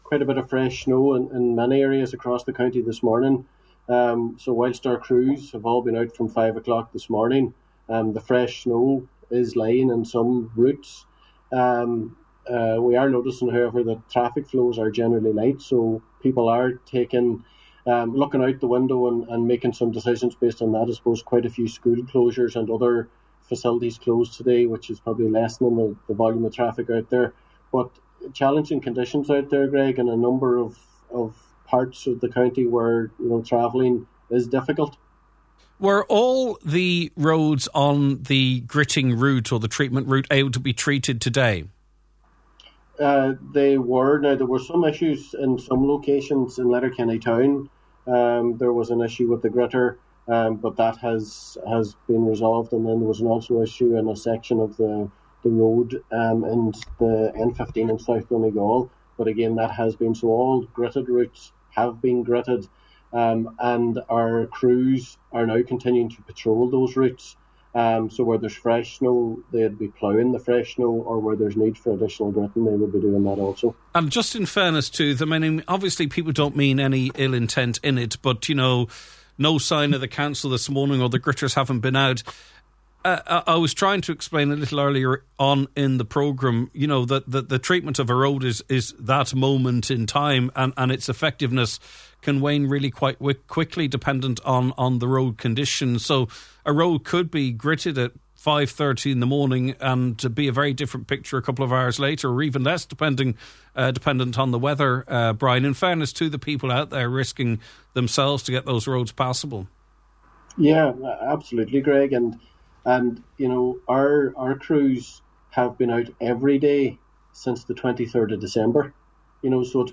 full discussion